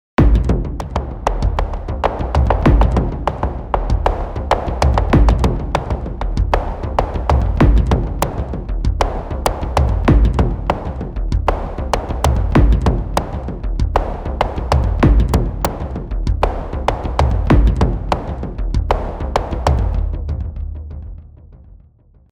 Oszillator-Modelle am Beispiel der Snare
Auch hier ist ein Sample am Werk, dass wir durch eine virtuell-analoge Snare ersetzen.
… ergeben ein regelrechtes Kraftpaket, wenn es um Snare-Sounds geht. Damit ist wirklich alles möglich, auch Klänge, die mit einer Snare nicht mehr viel zu tun haben, etwa analoge Windgeräusche oder futuristische, synthetische Decays.